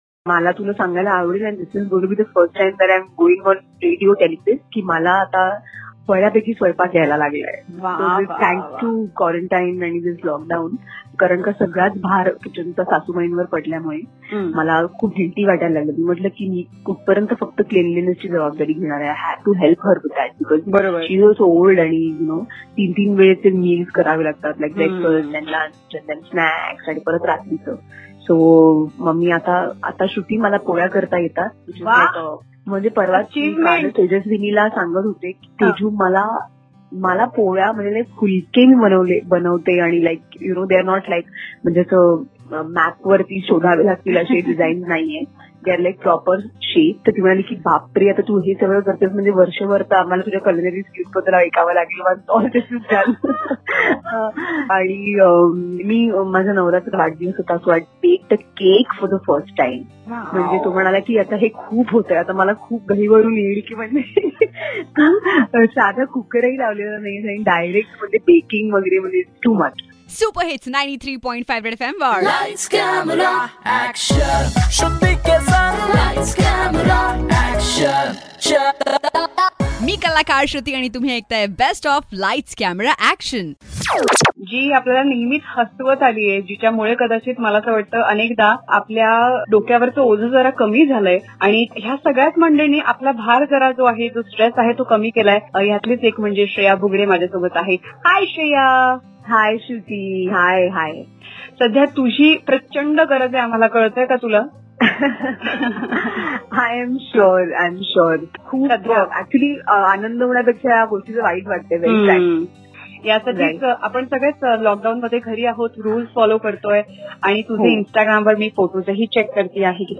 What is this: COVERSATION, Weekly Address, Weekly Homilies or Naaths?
COVERSATION